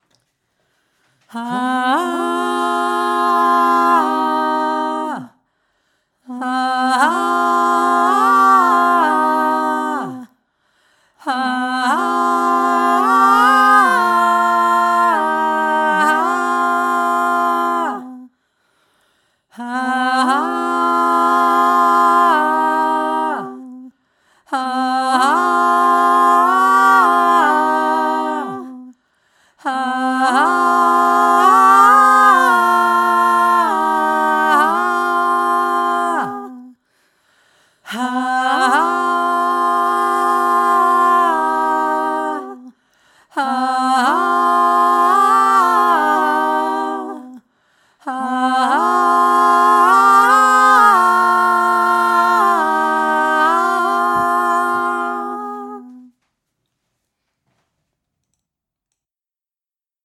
Wir habens nur zweistimmig gesunngen, aber auch hier dachte ich mir während der Aufnahme es könnte eine dritte Stimme gut dazu passen
Dreistimmig
Hauptstimme